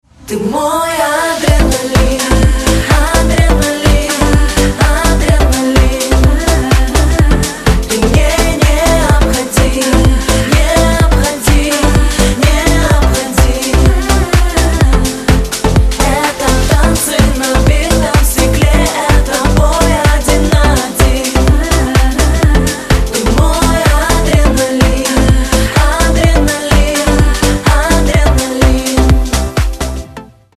• Качество: 320, Stereo
поп
женский вокал
club